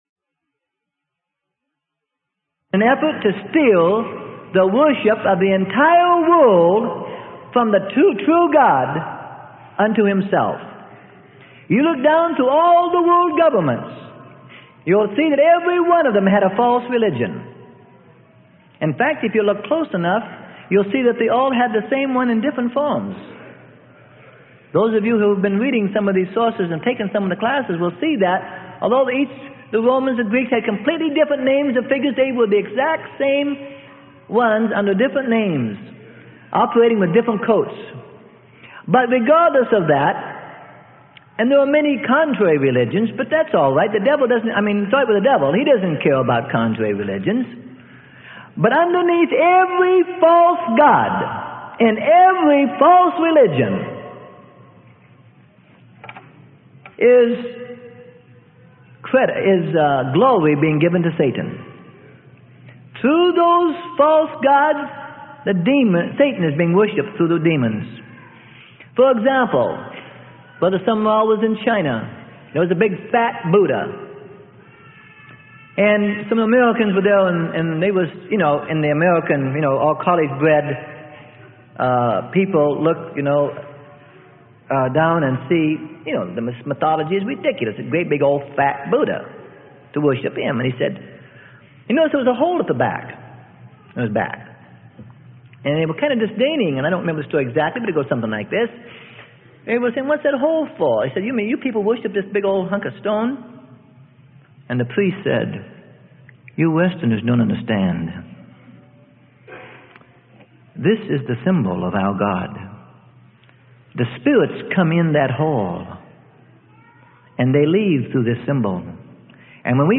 Sermon: Toward World Control: The Controllers - Freely Given Online Library